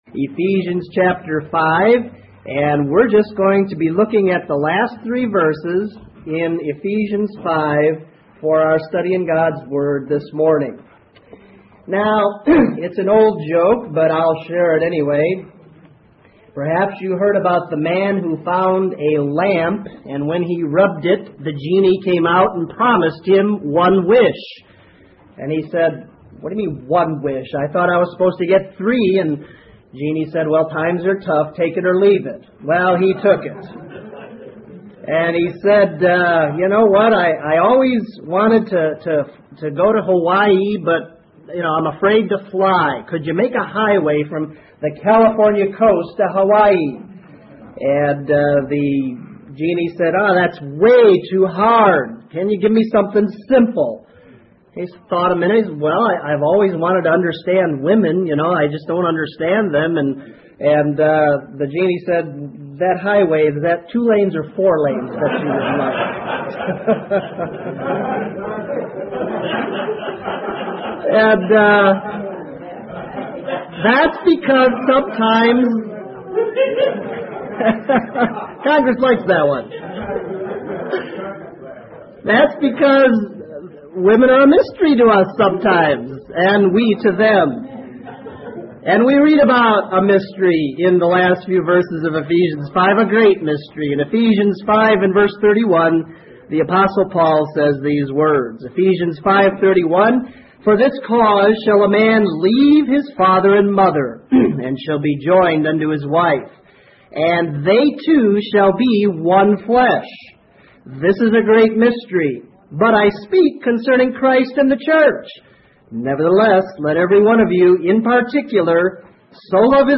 Lesson 80: Ephesians 5:32-33